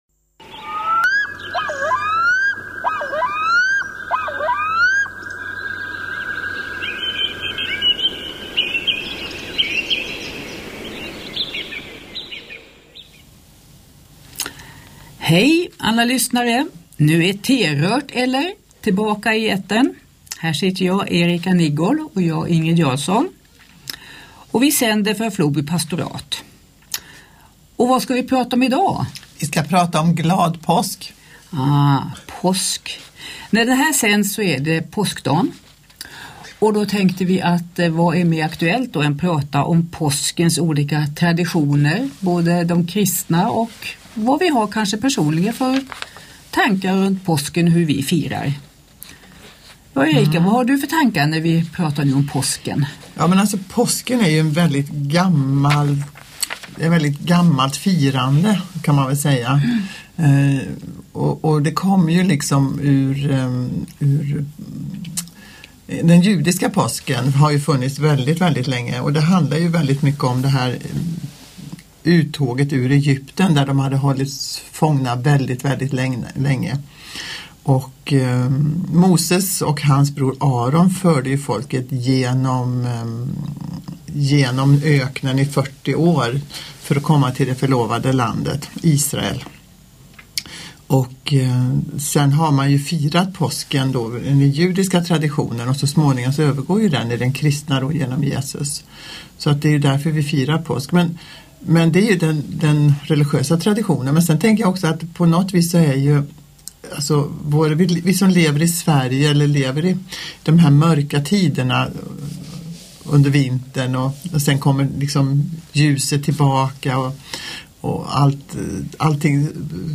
Det blir reflektioner om fasta, stilla veckan och uppståndelsens kraft, men också personliga berättelser om smörgåstårtor, påskägg som göms (och glöms!) i trädgården och hur högtider förändras med tiden. Podden är inspelad i samarbete med Floby pastorat.